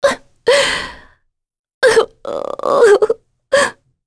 Talisha-Vox_Sad_1.wav